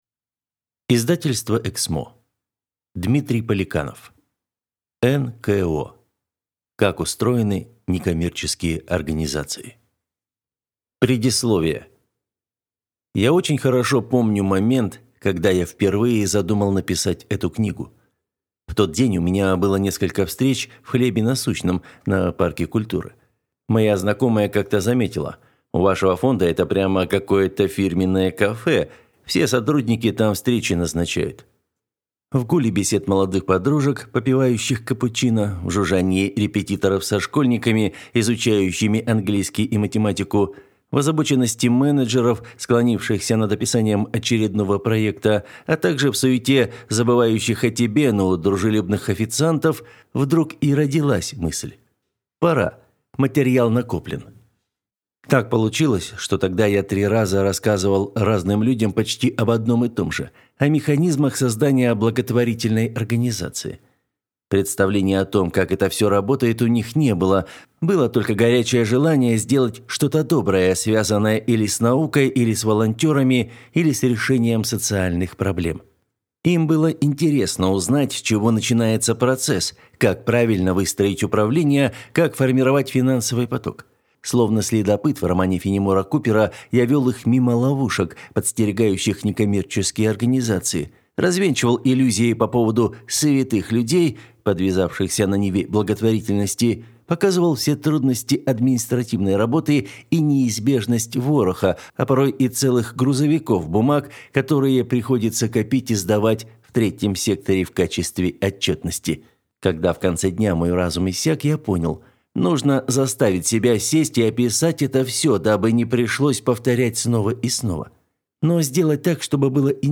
Аудиокнига НКО. Как устроены некоммерческие организации | Библиотека аудиокниг
Прослушать и бесплатно скачать фрагмент аудиокниги